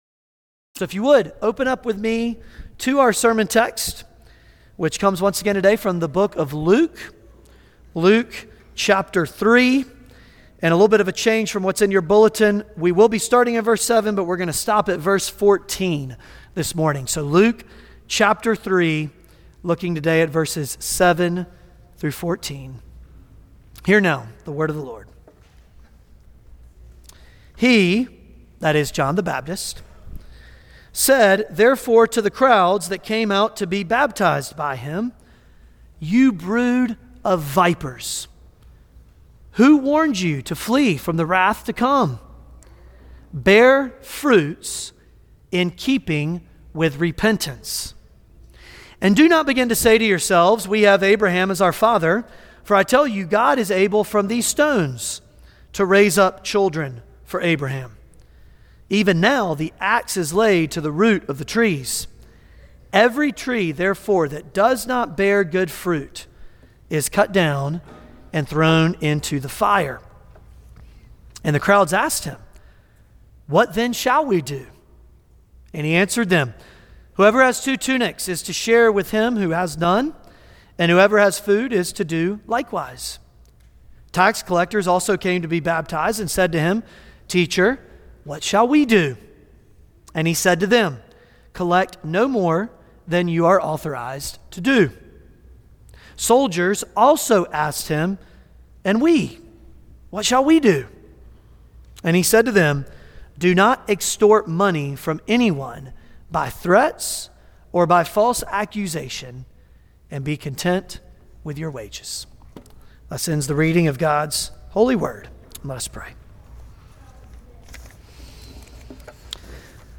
Luke Passage: Luke 3:7-20 Service Type: Sunday Morning Luke 3:7-20 « John The Baptist